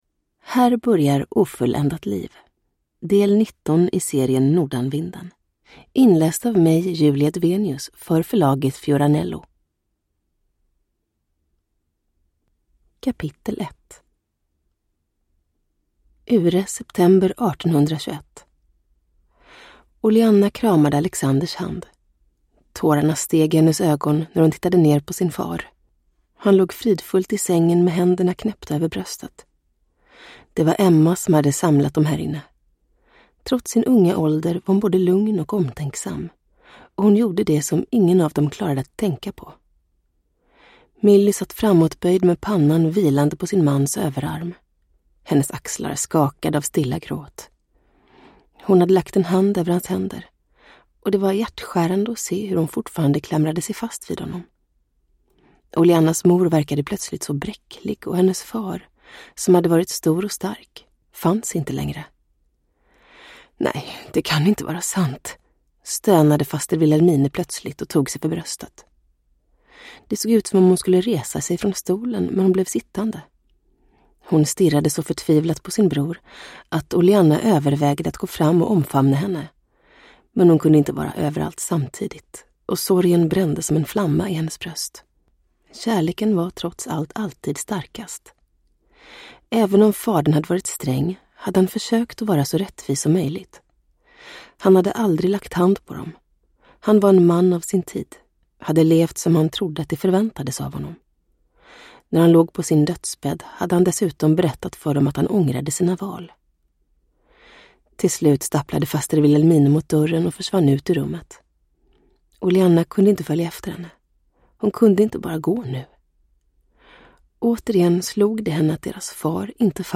Ofulländat liv – Ljudbok
Uppläsare: Julia Dufvenius